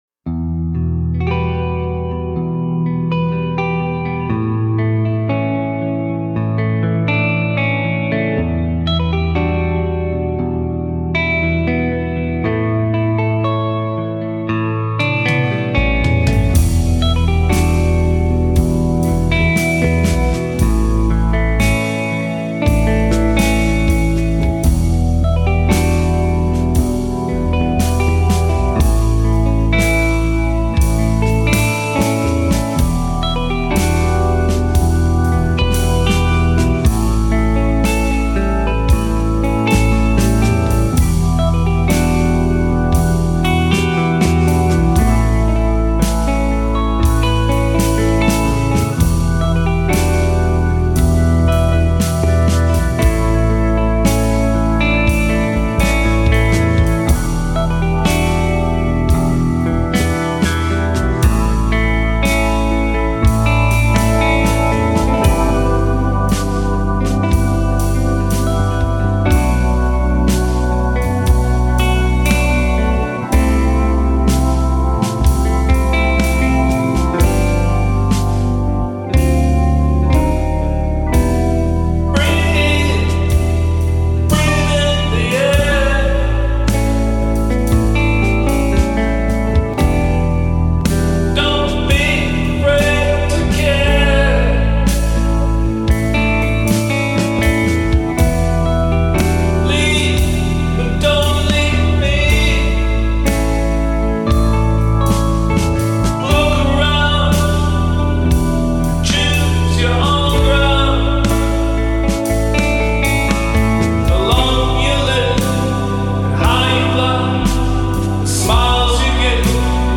Progressive covers used to learn multitrack recording